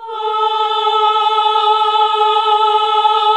AAH A2 -R.wav